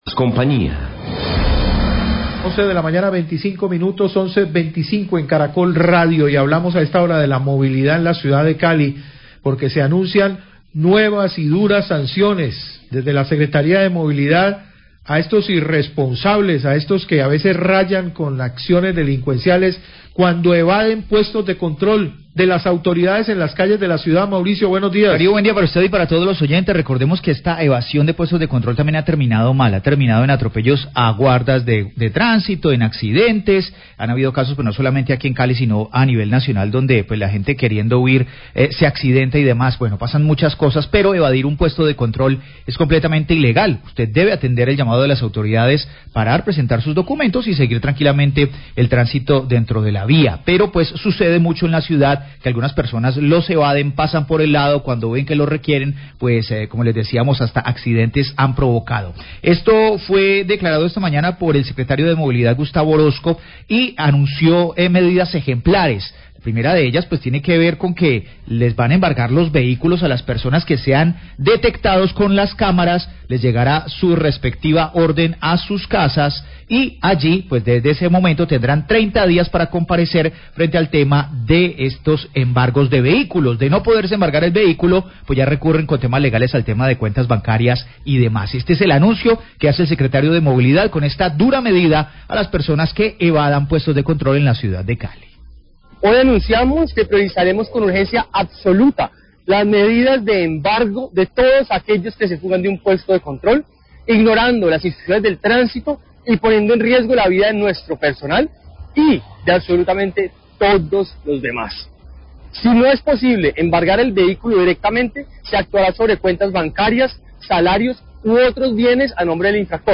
Radio
Además, se expone audio de video donde el Secretario es increpado.